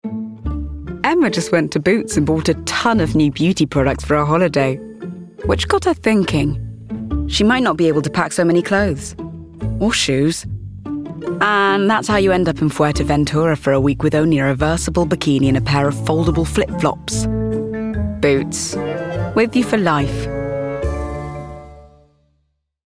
RP
Female
Husky
Warm
BOOTS COMMERCIAL